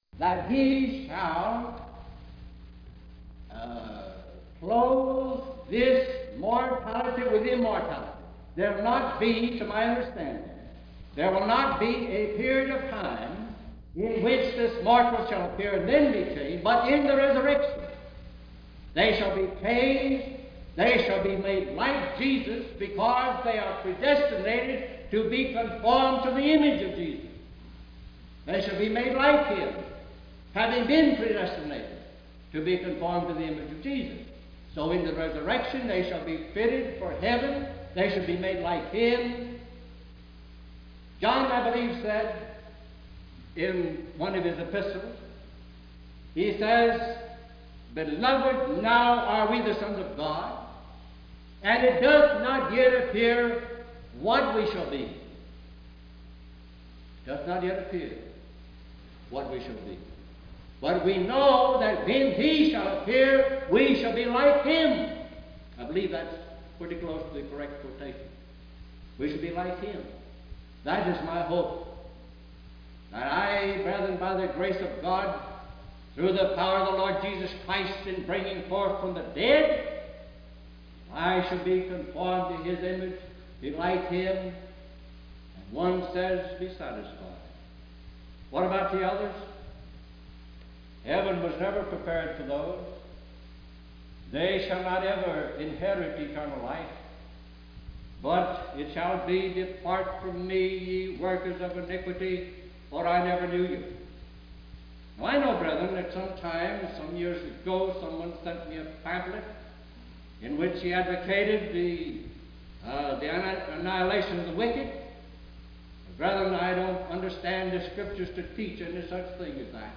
Sermons and singing from the 1956 Lexington-Roxbury Association meeting